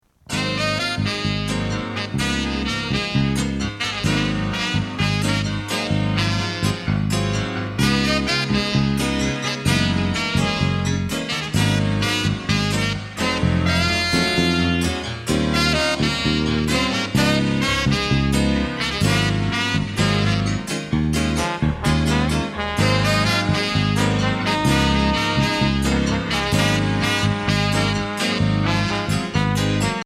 Voicing: Drum Set